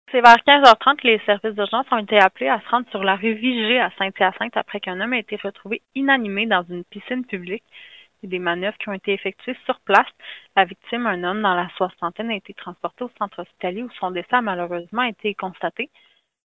porte-parole